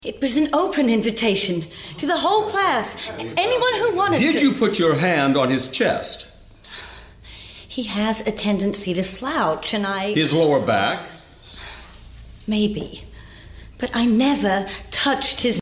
Warm, fun, engaging and natural Lucinda has worked as voice artist extensively for over 30 years both here and in the UK in various Animations including “Koala Brothers”, BBC Radio Drama “Our Father Who Art In A Tree”, ”We Need to Talk About Kevin”
New York
Middle Aged